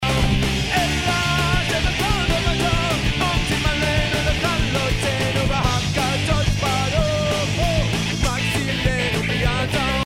they keep things fast, loud and furious.